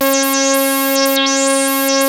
Index of /90_sSampleCDs/Hollywood Edge - Giorgio Moroder Rare Synthesizer Collection/Partition A/ARP 2600 7